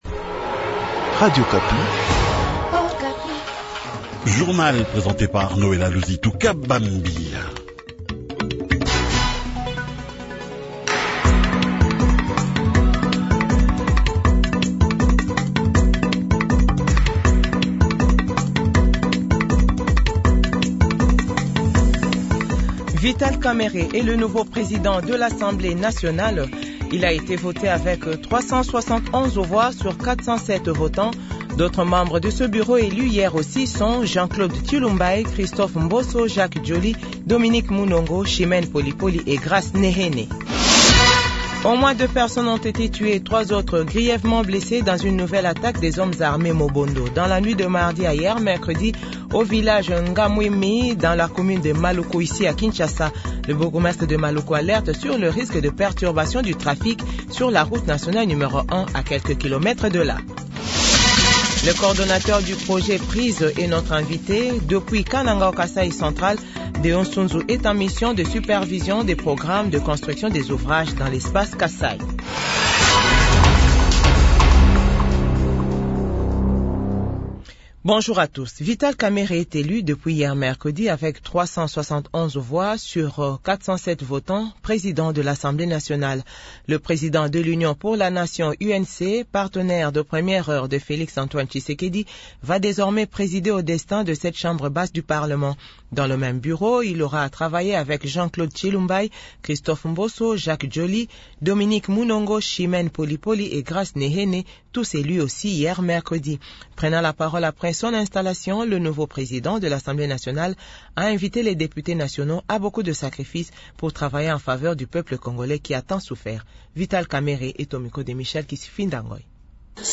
JOURNAL FRANCAIS 8H00
1/ KIN : Election des membres du bureau définitif de l’Assemblée nationale ; extrait du discours de V. Kamerhe